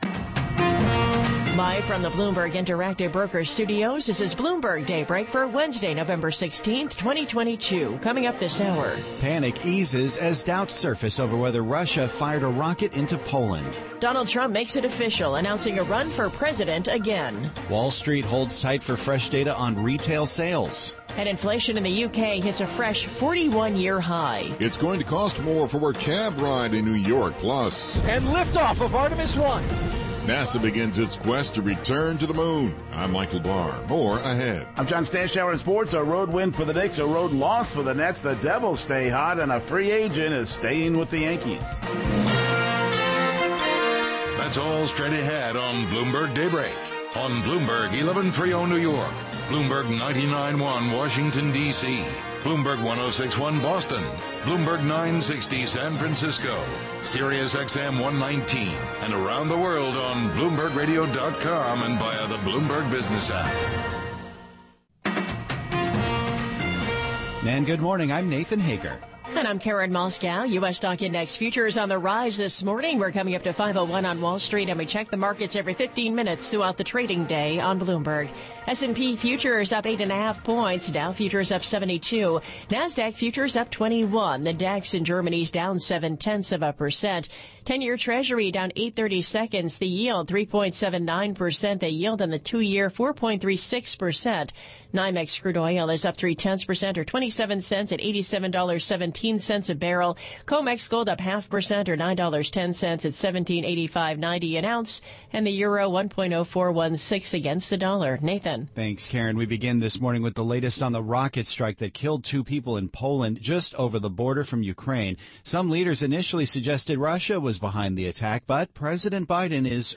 Bloomberg Daybreak: November 16, 2022 - Hour 1 (Radio)